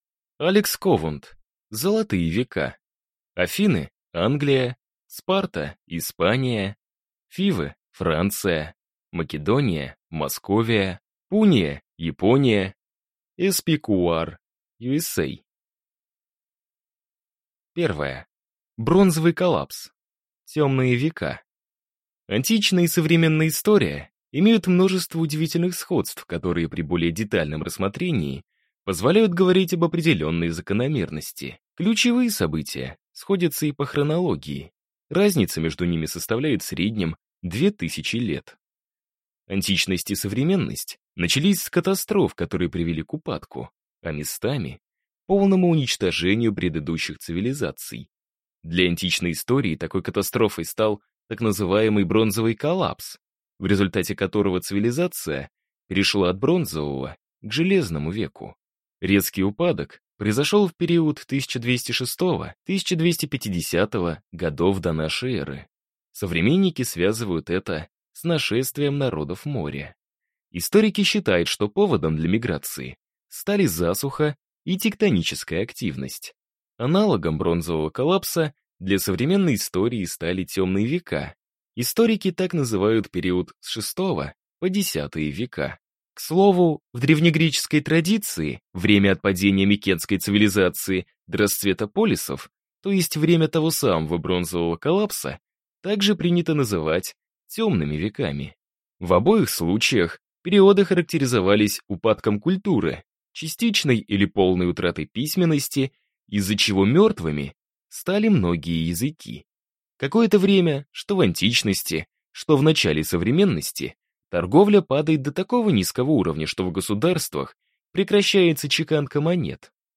Аудиокнига Золотые века: Афины – Англия, Спарта – Испания, Фивы – Франция, Македония – Московия, Пуния – Япония, SPQR – USA | Библиотека аудиокниг